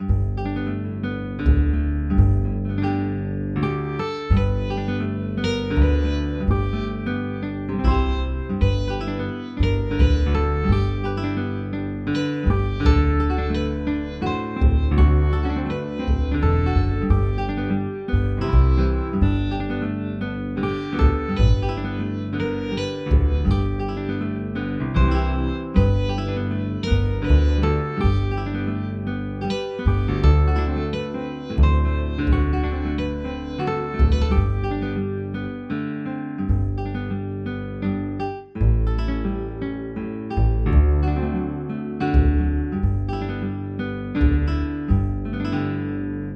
Instrumental | Downloadable  GO Download/Print